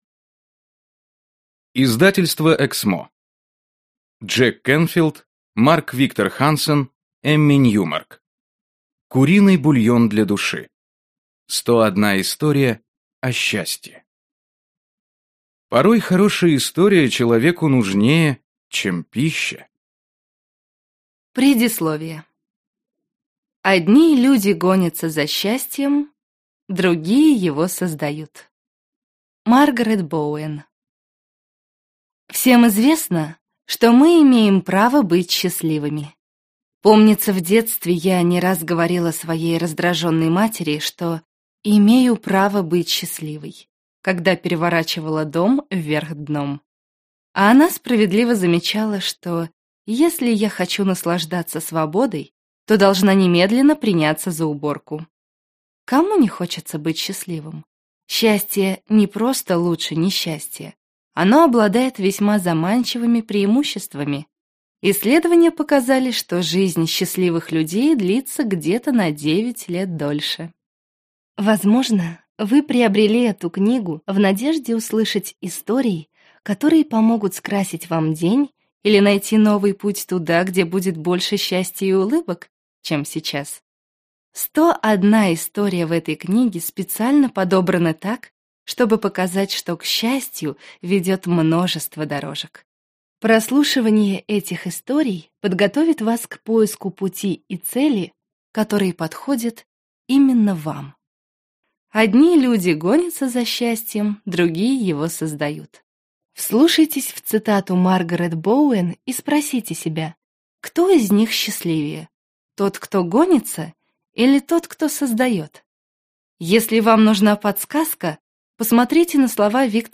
Аудиокнига Куриный бульон для души. 101 история о счастье | Библиотека аудиокниг